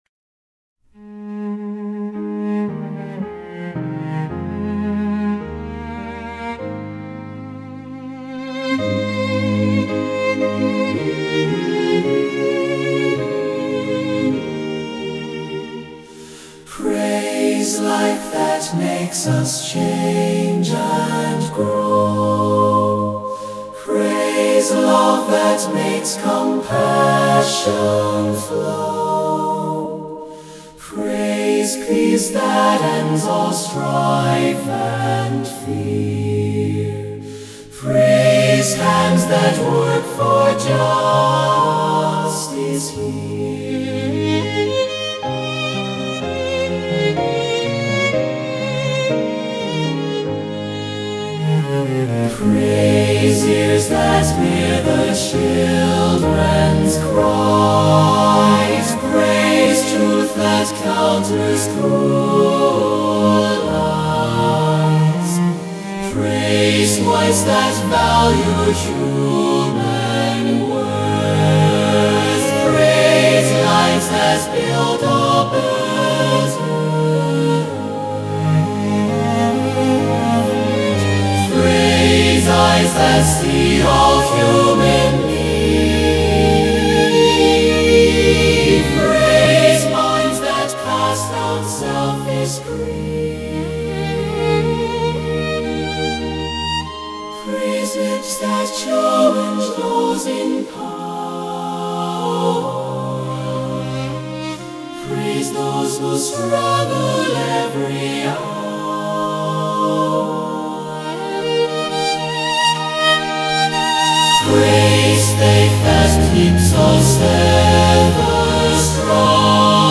Since I have limited resources I used AI (artificial intelligence ). I instructed the Suno software to create a small choir (SATB) and accompany the voices with piano and string trio (violin, viola, and cello).  They pretty much stuck to the melodies except for the third verse where they go a little wild.